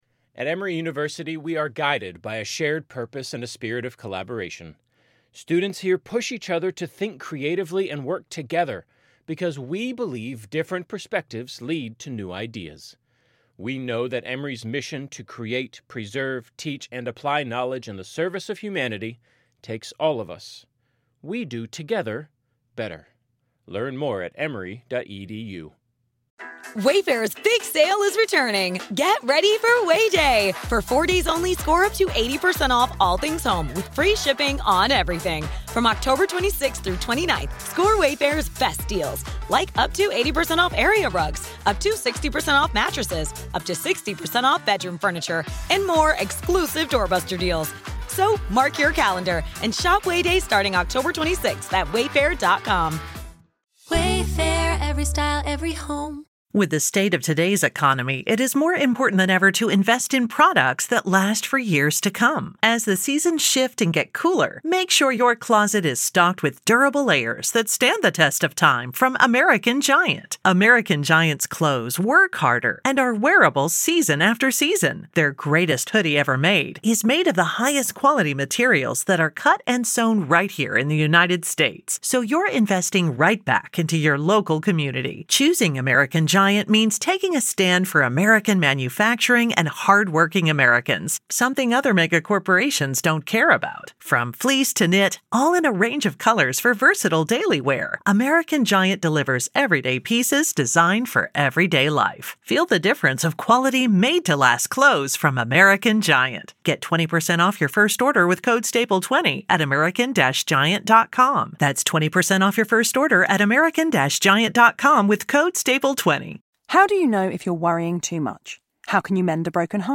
Comedians and dearest pals Tom Allen and Suzi Ruffell chat friendship, love, life and culture....sometimes....